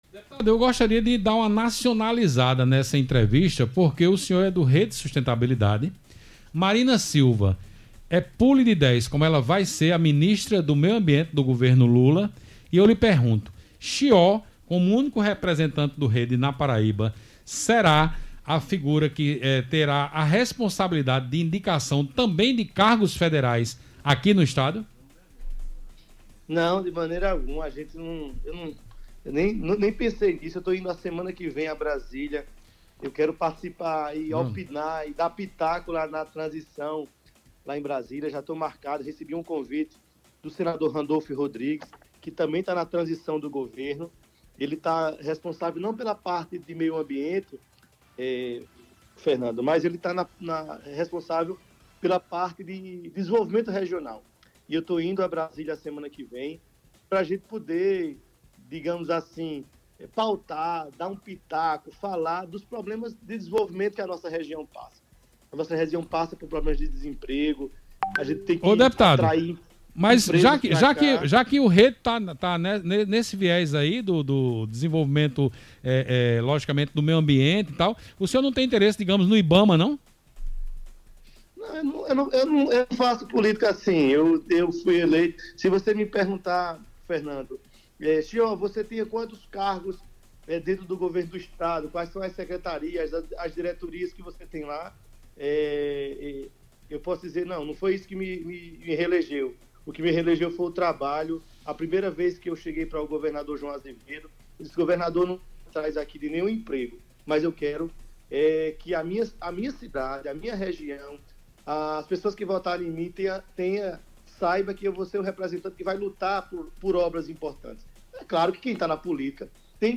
O deputado estadual reeleito, Chió (Rede), revelou durante entrevista ao programa 60 Minutos, do Sistema Arapuan de Comunicação na noite desta segunda-feira (21), que foi convidado pelo ex-coordenador de campanha do presidente eleito Luiz Inácio Lula da Silva (PT), o senador Randolfe Rodrigues (Rede), para participar do processo de transição, em Brasília.